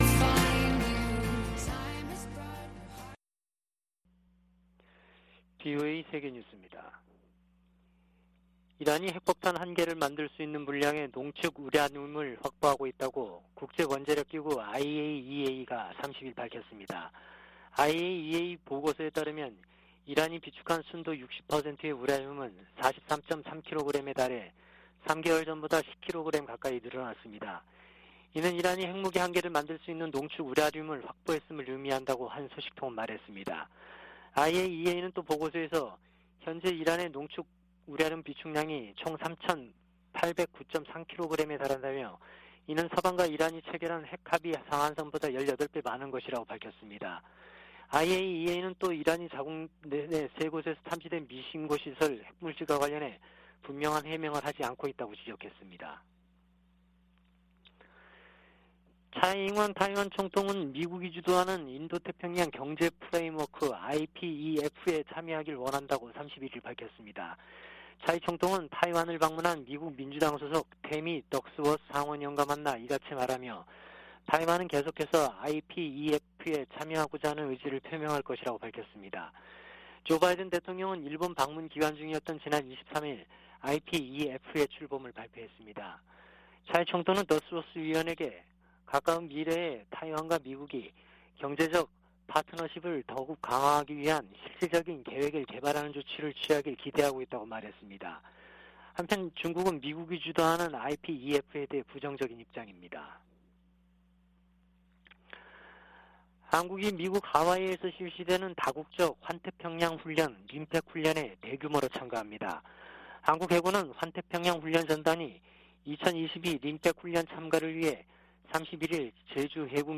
VOA 한국어 아침 뉴스 프로그램 '워싱턴 뉴스 광장' 2022년 6월 1일 방송입니다. 조 바이든 미국 대통령이 메모리얼데이를 맞아 미군 참전 용사들의 희생을 기리고, 자유민주주의의 소중함을 강조했습니다.